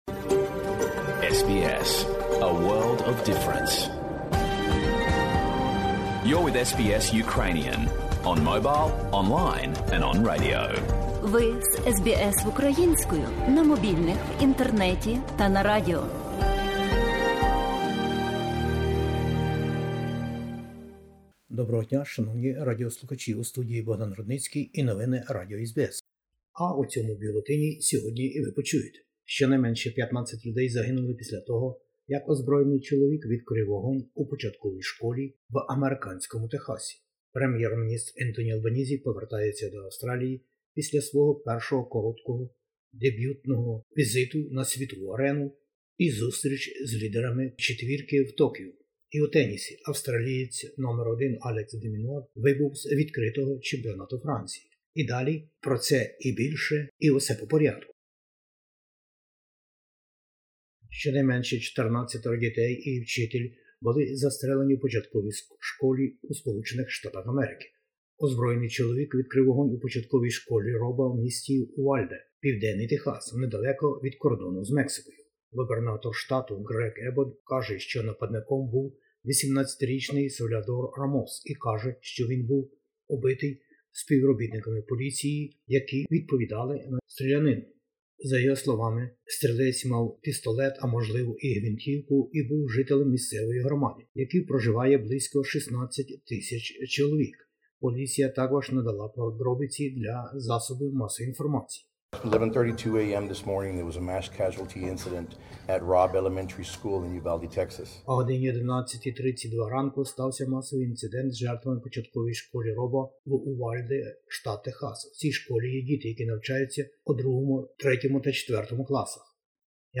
Бюлетень SBS новин українською мовою. Закордонна подорож нового Прем'єр-міністра Австралії.